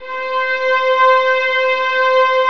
VIOLINS .2-R.wav